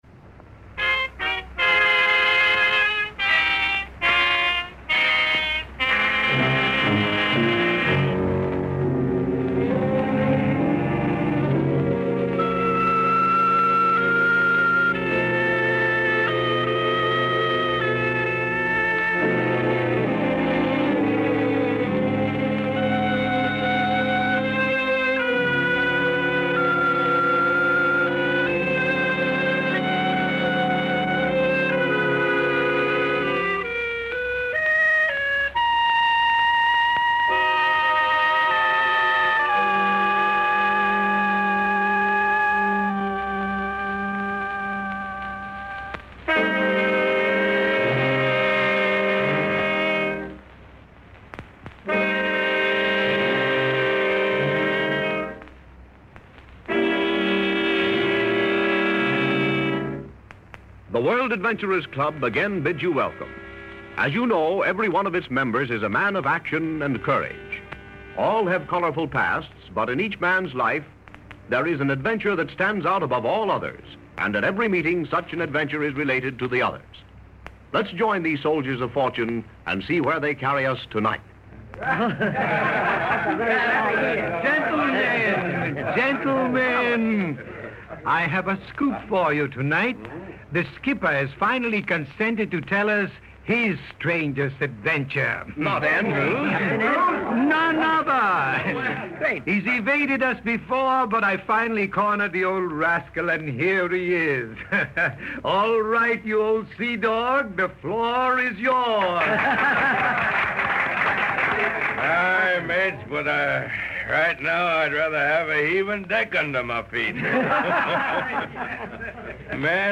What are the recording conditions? The Adventure Club radio show Step into the world of thrilling escapades and daring exploits with "The Adventure Club" radio show! This auditory treasure trove is a throwback to the golden age of radio, where imagination knew no bounds, and every episode promised a new horizon to explore.